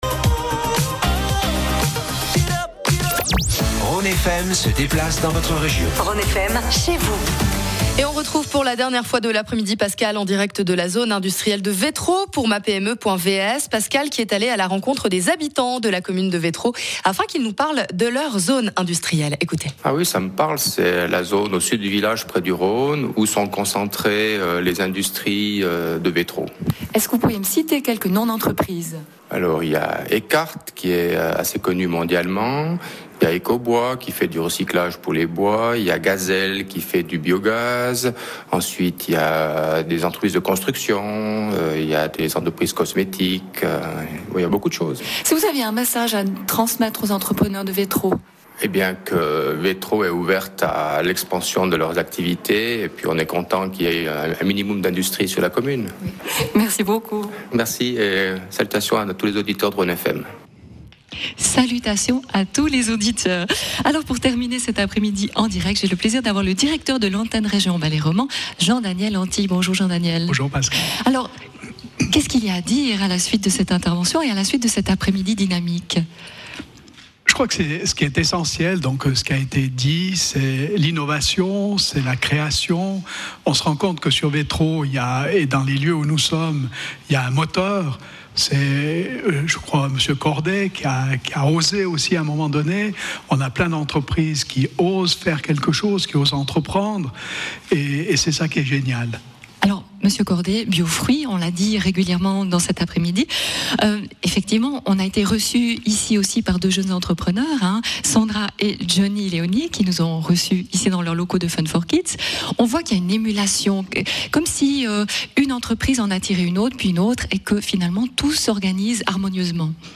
Suite à une présentation du tissu économique par le Président de commune, les interviews en direct de plusieurs entrepreneurs vous permettront de mieux connaitre une commune et son économie !
Interview de